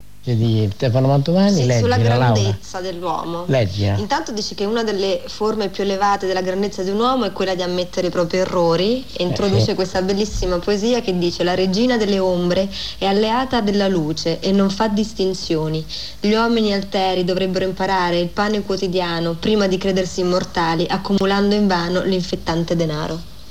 Il file audio è tratto da “L’uomo della notte”, programma su Radio 1 Rai nato negli anni ’70, ripreso poi nel 2007 da Maurizio Costanzo.